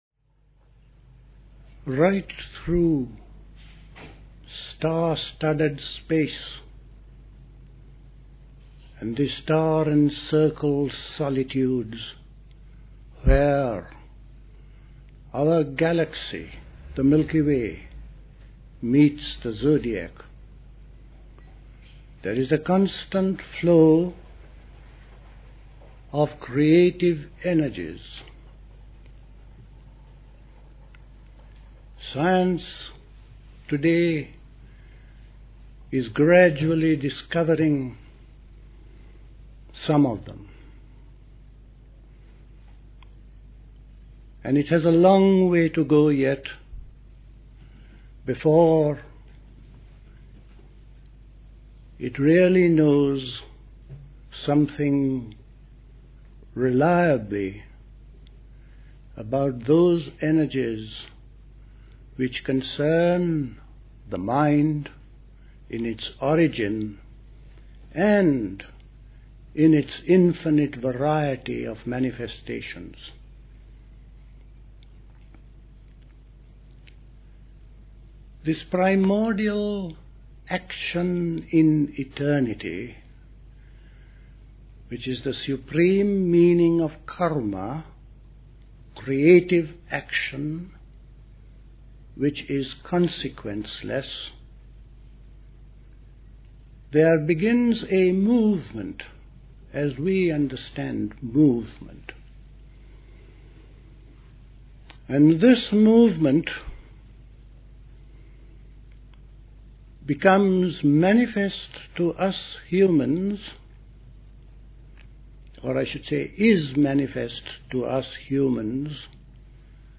Recorded at the 1974 Park Place Summer School.